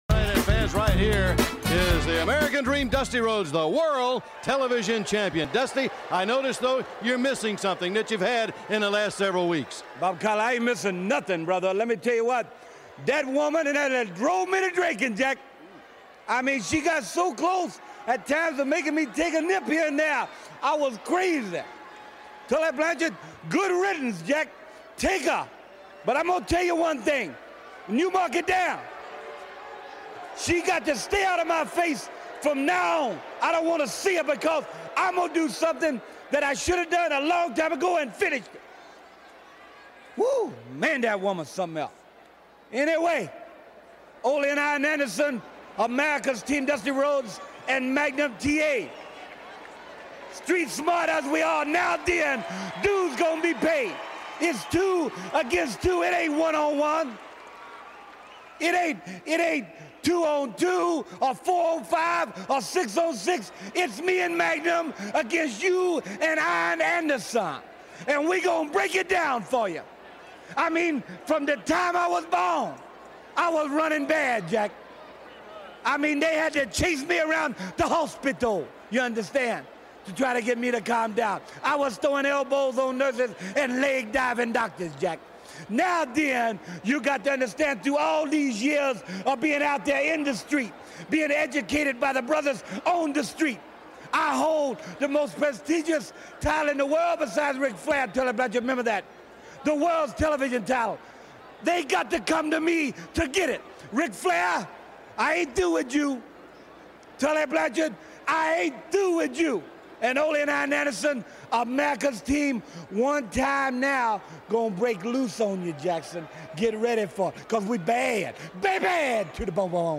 tests out a new audio set up. Previewing this weeks AEW “WrestleDream” and NJPW’s “King Of Pro Wrestling” events. This weeks Throwback is a lookback on WCW’s “Halloween Havoc” PPV from October of 1998.